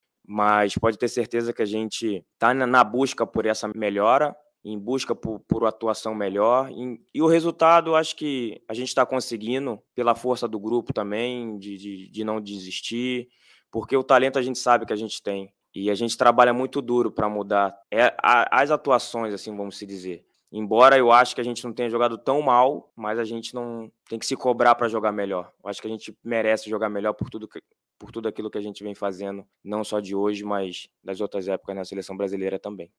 Em entrevista coletiva em Manaus, o zagueiro da seleção brasileira Thiago Silva comentou sobre esse momento histórico para a capital do Amazonas e sobre a recepção em Manaus, ouça: